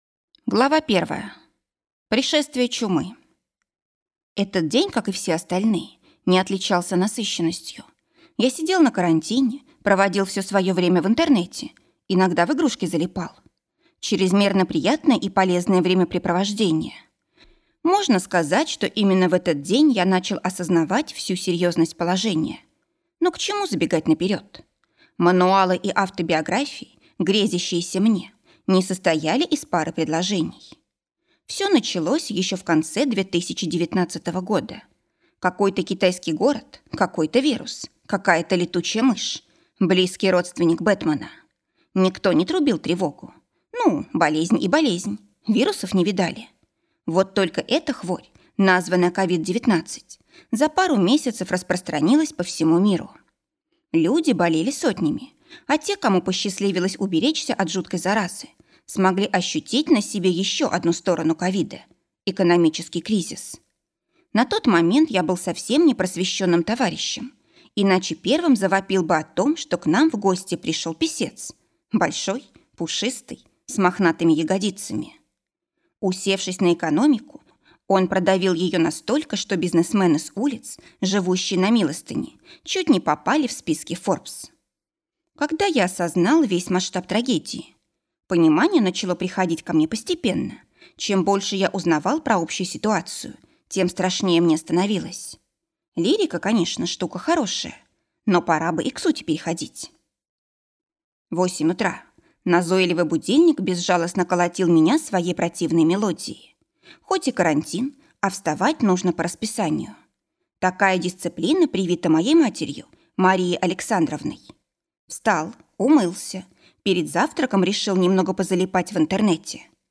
Аудиокнига Я из 2020 | Библиотека аудиокниг